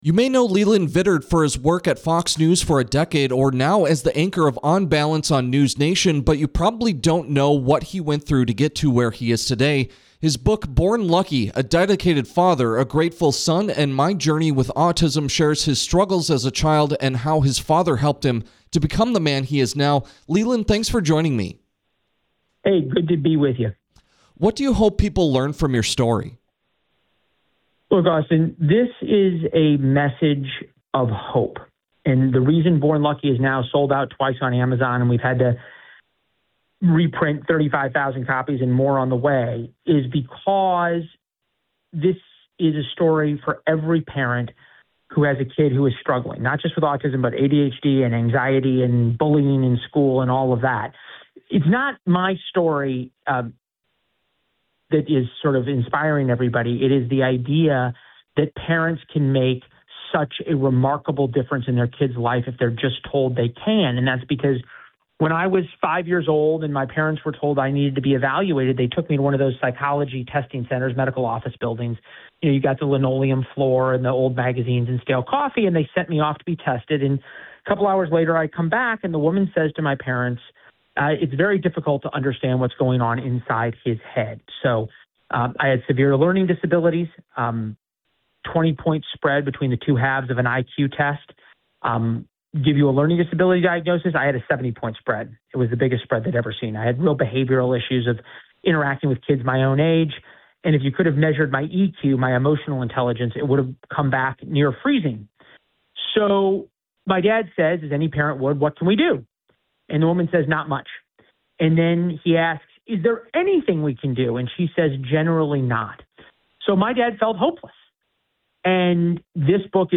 In an interview with Flag Family News, Vittert called his autobiography a message of hope.
leland-vittert-interview-1.mp3